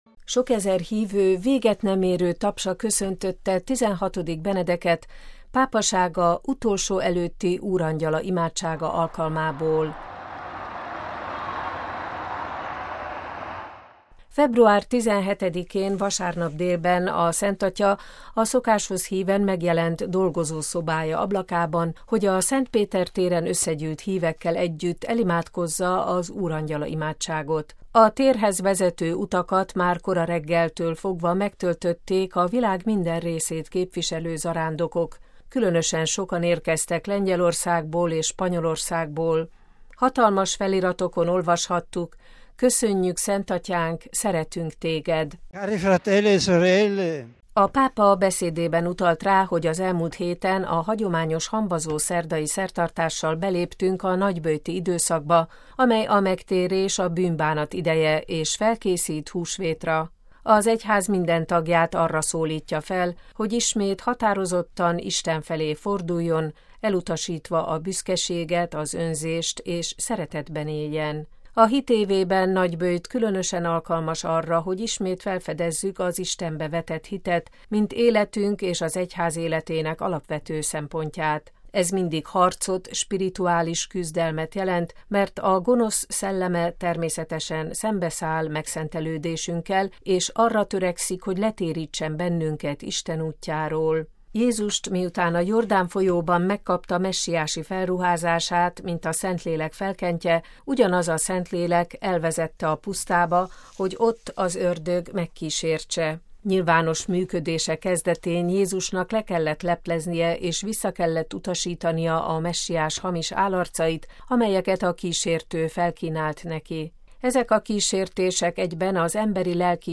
Sokezer hívő véget nem érő tapsa köszöntötte XVI. Benedeket pápasága utolsó előtti Úrangyala imádsága alkalmából
MP3 Február 17-én, vasárnap délben a Szentatya a szokáshoz híven megjelent dolgozószobája ablakában, hogy a Szent Péter téren összegyűlt hívekkel együtt elimádkozza az Úrangyala imádságot.